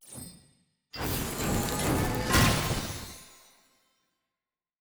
sfx-tft-skilltree-ceremony-fire-division-up.ogg